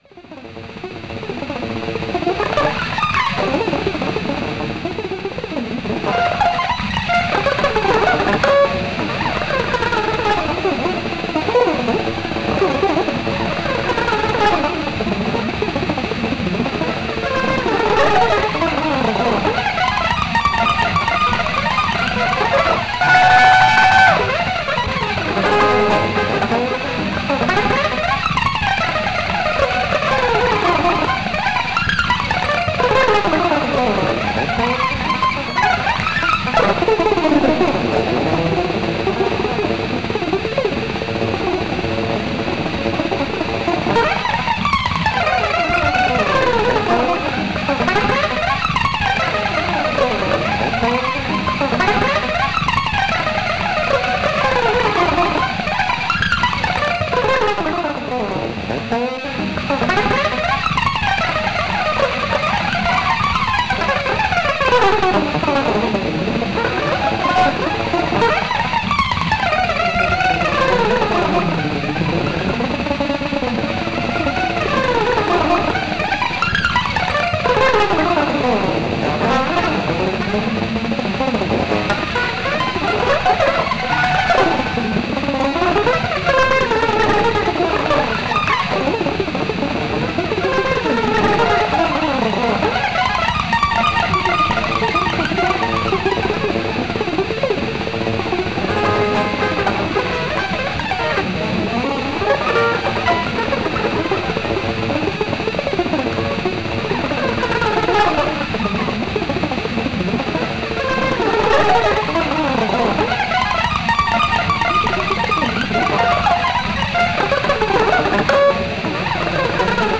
, orchestrated Glossolalia.
A large amount of automatism melodies
which was given out from the ultra high-speed guitar playing
is edited, arranged and unified by computer.